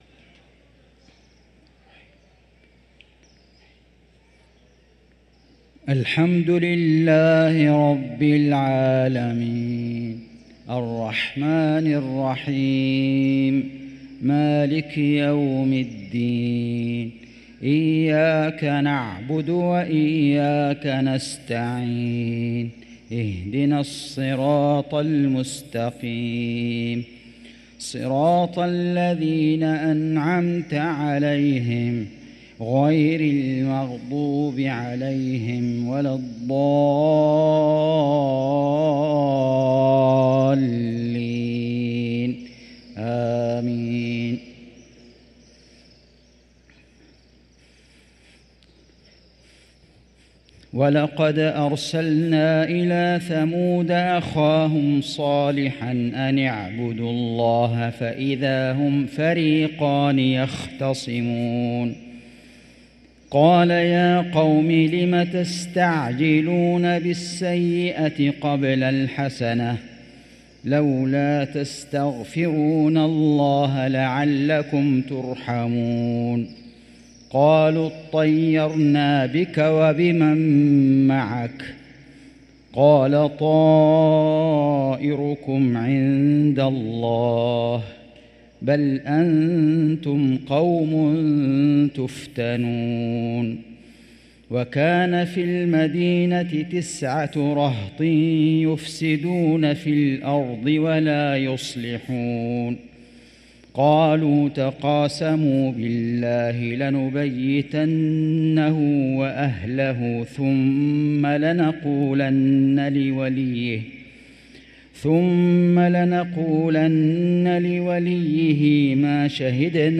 صلاة العشاء للقارئ فيصل غزاوي 3 رجب 1445 هـ
تِلَاوَات الْحَرَمَيْن .